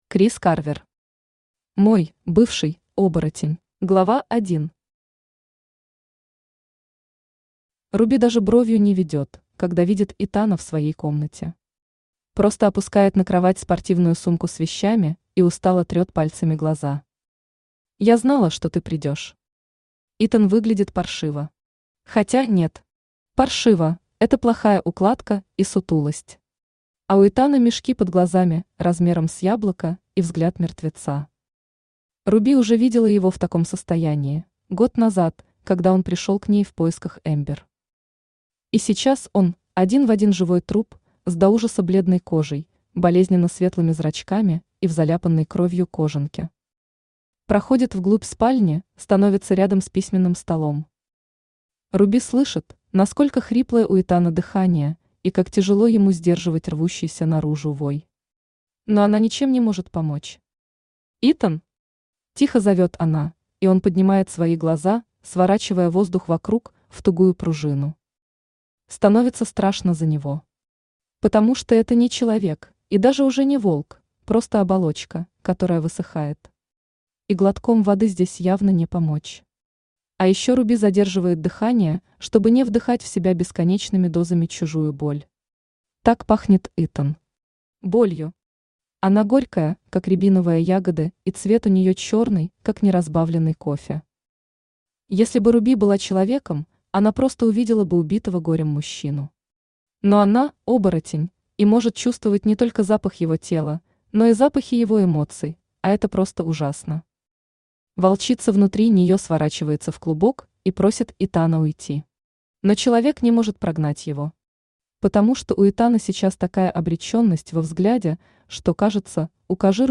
Аудиокнига Мой (бывший) оборотень | Библиотека аудиокниг
Aудиокнига Мой (бывший) оборотень Автор Крис Карвер Читает аудиокнигу Авточтец ЛитРес.